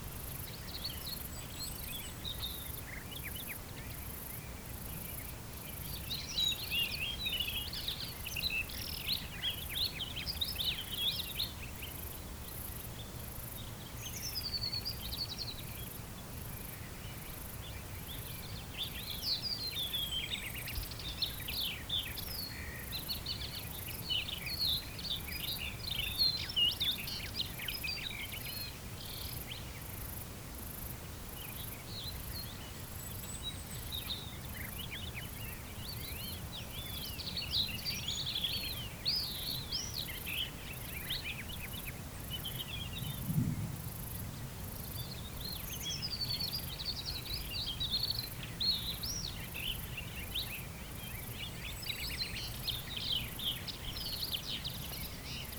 meadow_day.ogg